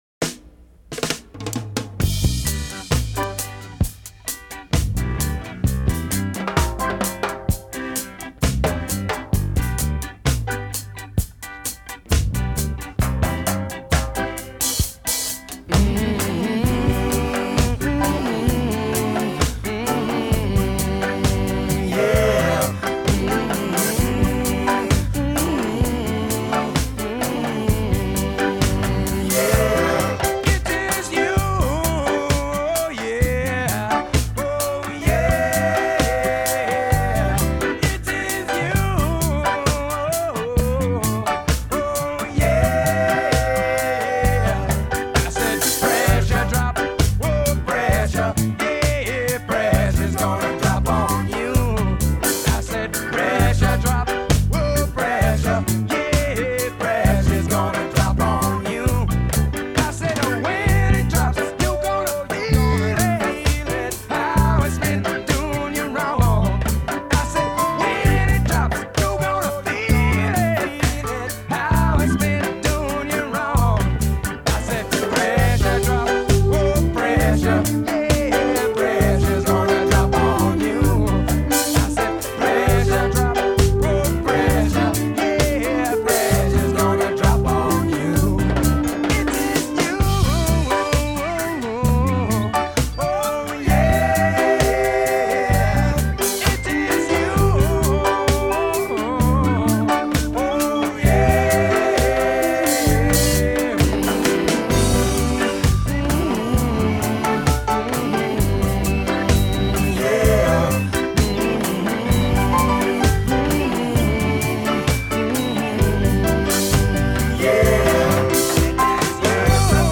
Few white guys can pull off reggae covers